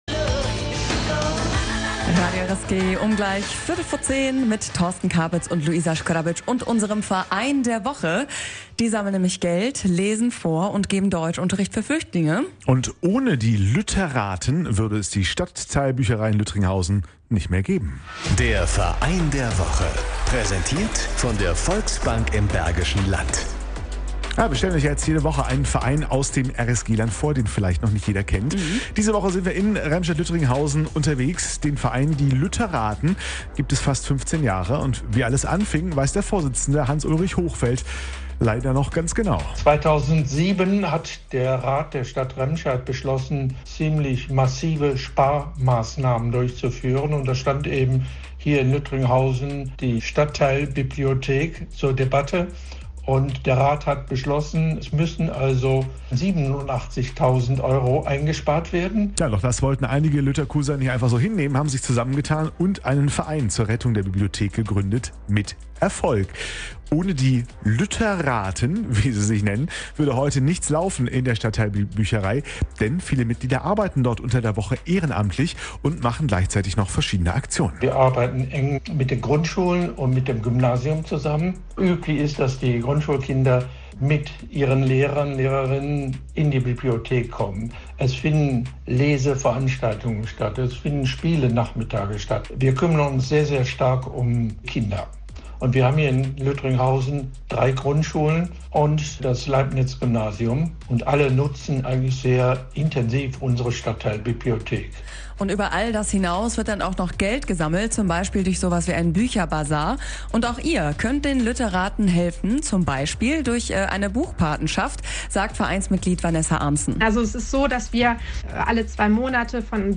„Verein der Woche“ Teil 2 Live Mittschnitt vom 12.02.22 bei Radio RSG